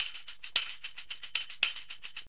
TAMB LP 112.wav